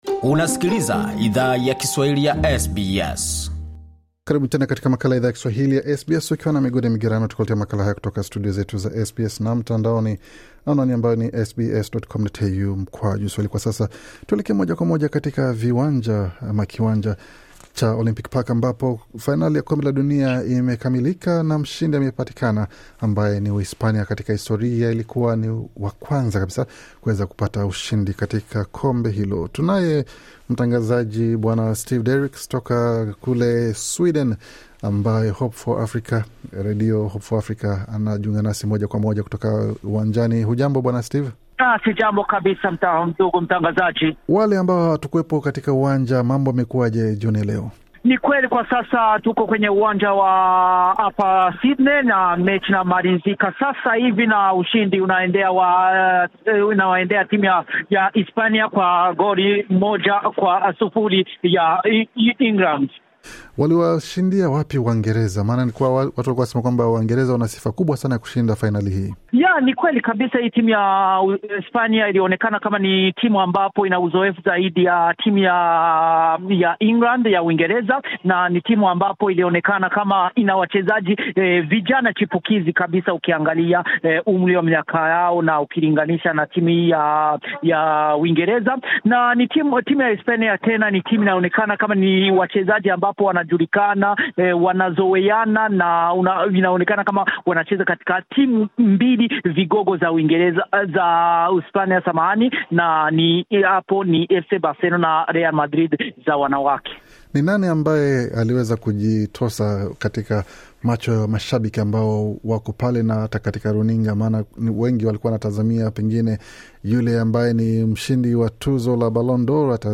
alitupasha mubashara kutoka uwanjani yaliyo jiri katika pambano hilo.